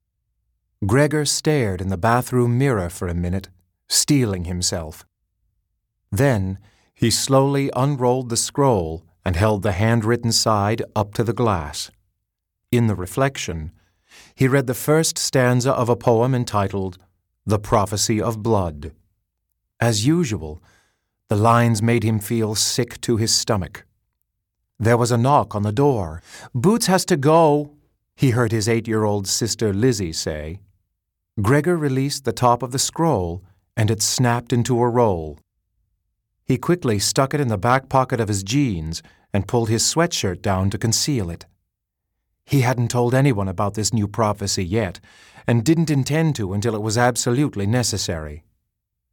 Curse-of-the-Warmbloods-Audio-Book--Sample.mp3